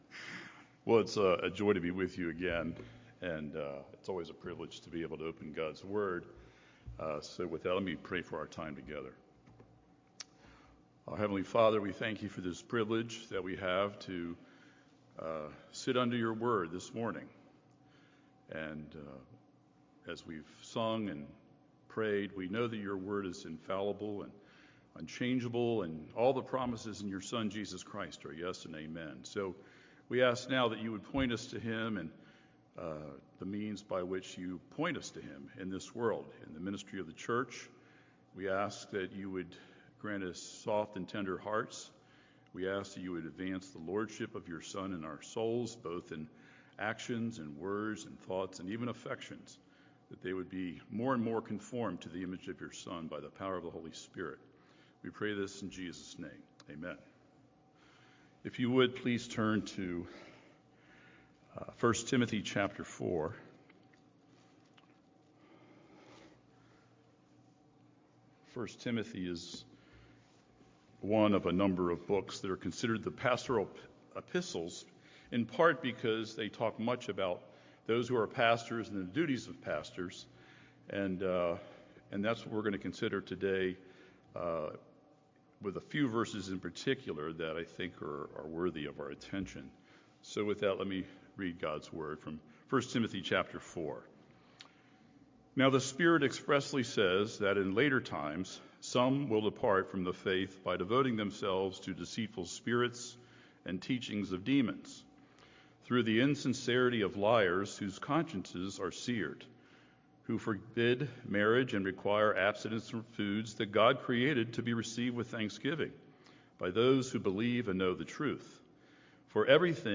Taking Stock in the Light: Sermon on 1Timothy 4:1-16 - New Hope Presbyterian Church